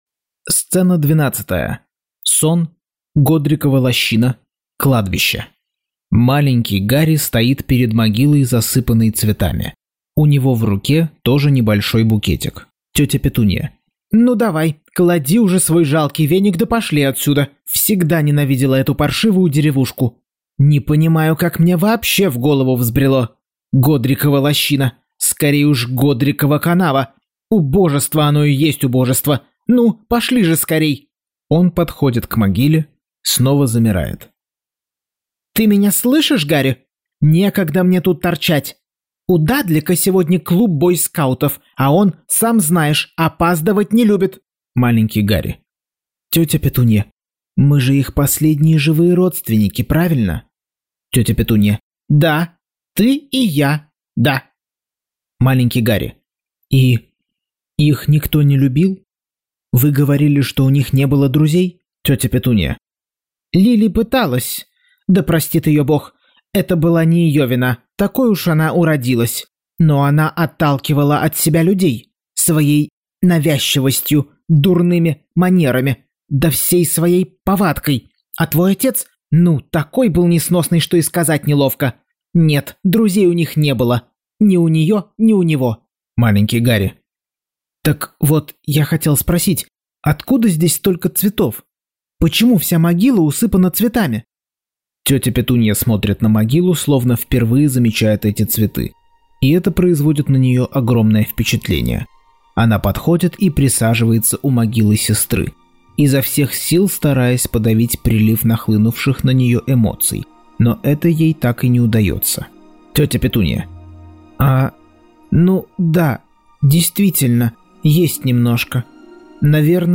Аудиокнига Гарри Поттер и проклятое дитя. Часть 44.